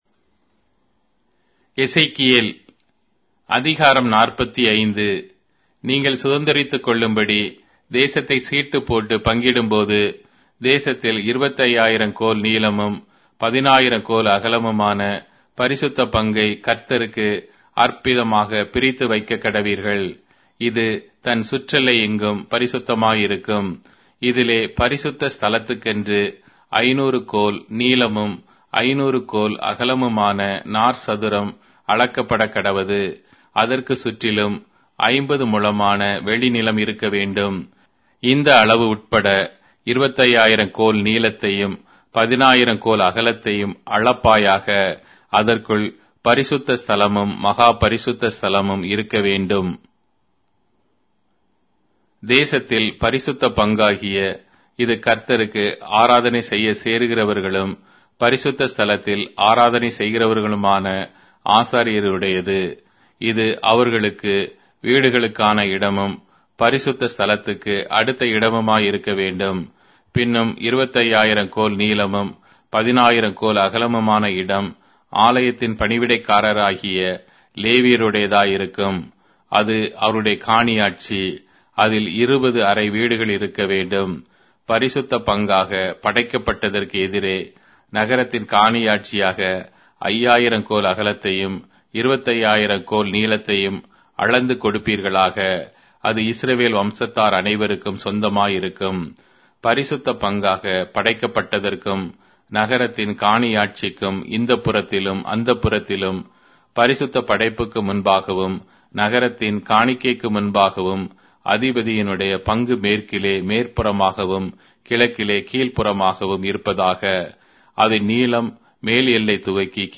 Tamil Audio Bible - Ezekiel 14 in Ervbn bible version